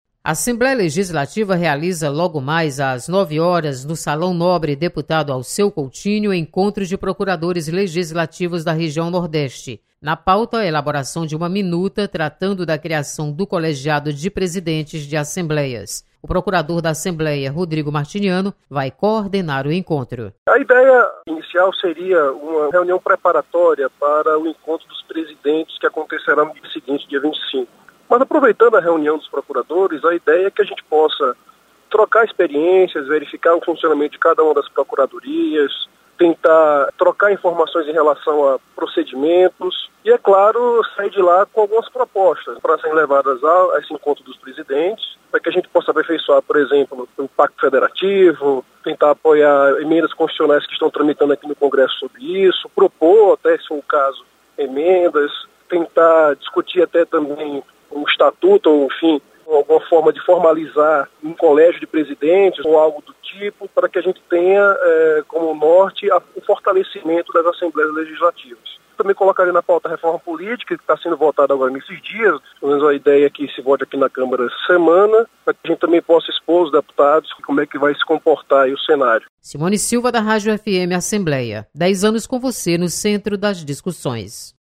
Encontro com procuradores das assembleia legislativas da região Nordeste acontece nesta quinta-feira. Repórter